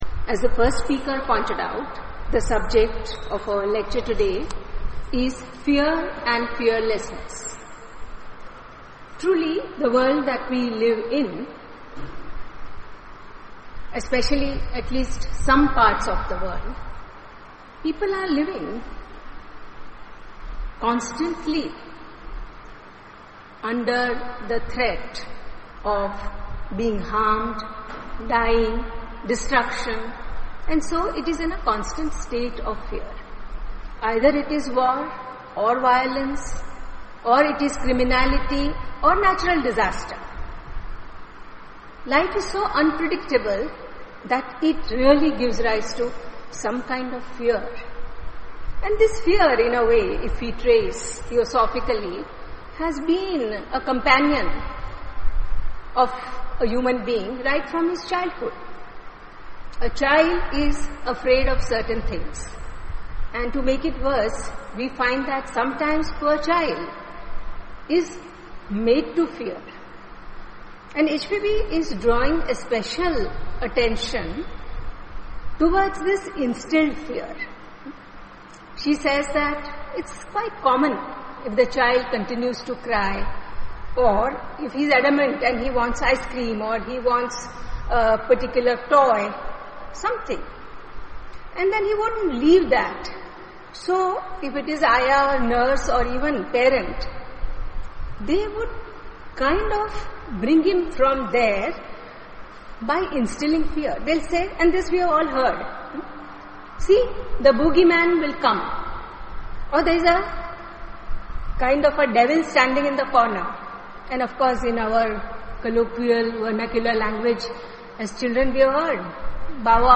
Uploaded Audio Lecture: Fear and Fearlessness
Dear Subscriber, An Audio Lecture entitled Fear and Fearlessness has been recorded and uploaded to our web site.